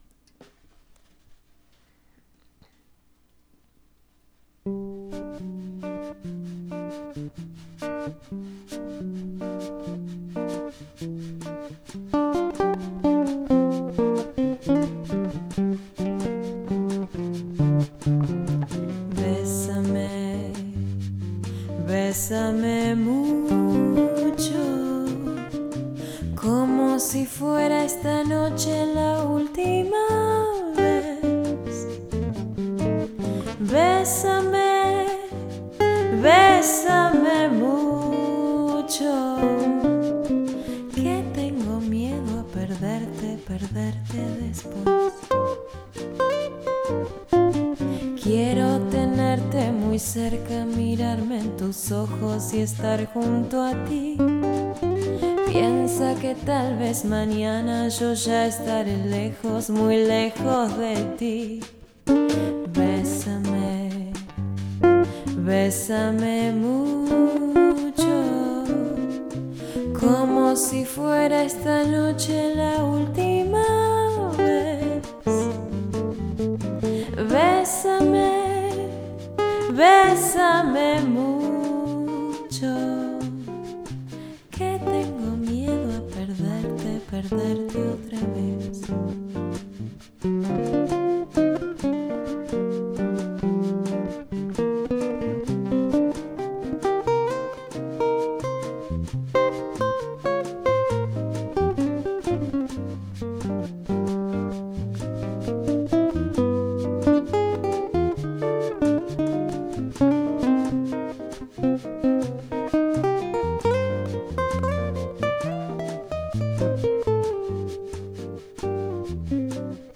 Guitar duet available